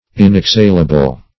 Search Result for " inexhalable" : The Collaborative International Dictionary of English v.0.48: Inexhalable \In`ex*hal"a*ble\, a. Incapable of being exhaled.